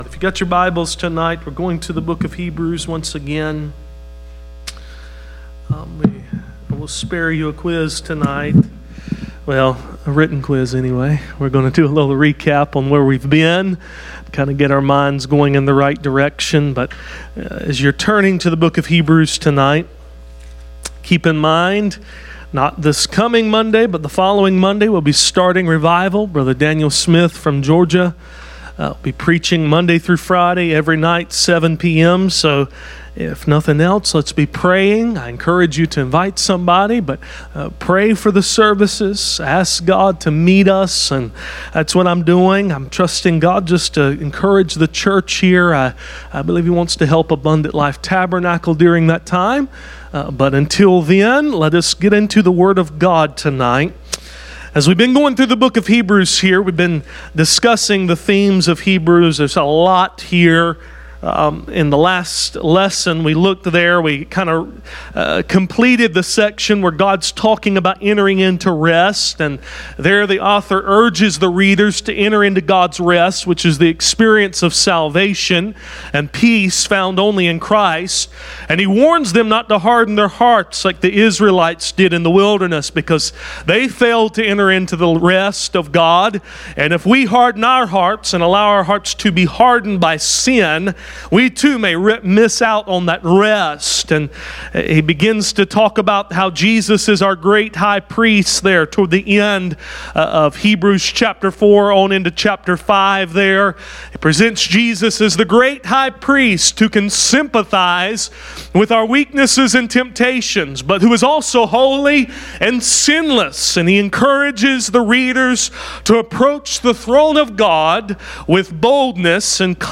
Study of Hebrews Passage: Hebrews 6-8 Service Type: Midweek Meeting « Are you ready for revival